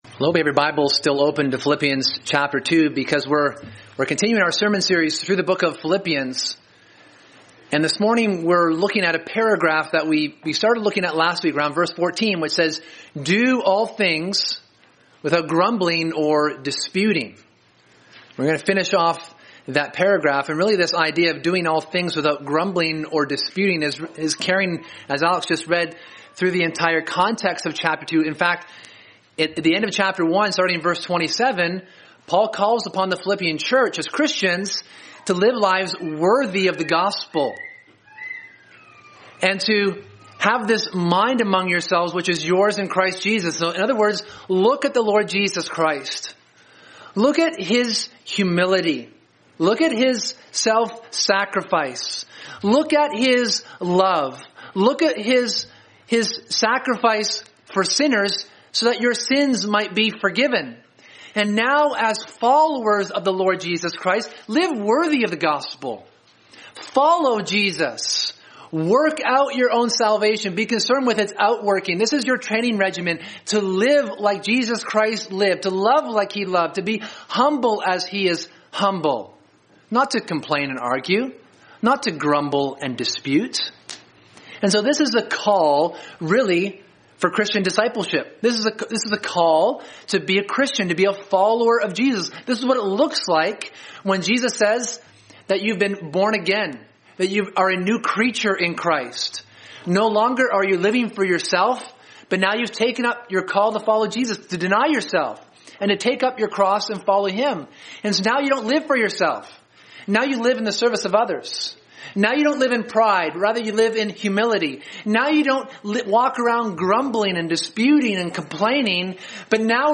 Sermon: Joy in Sacrificial Living